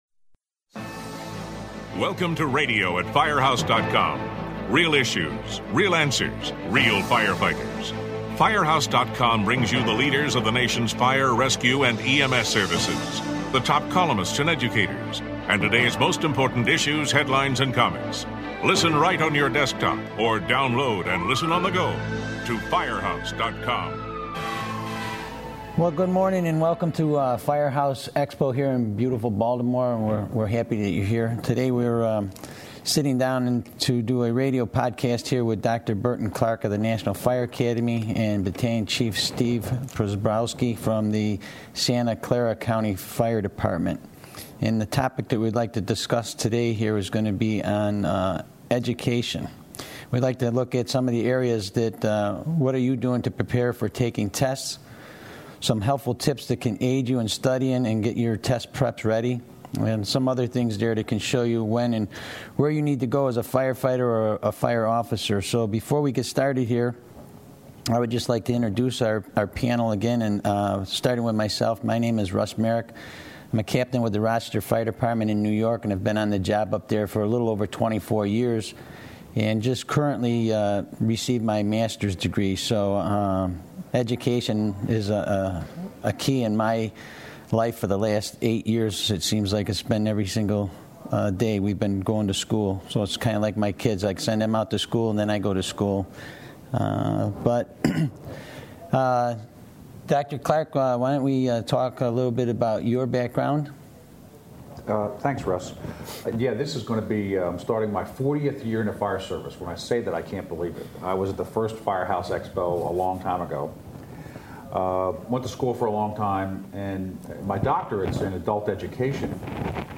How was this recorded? This podcast was recorded at Firehouse Expo in July.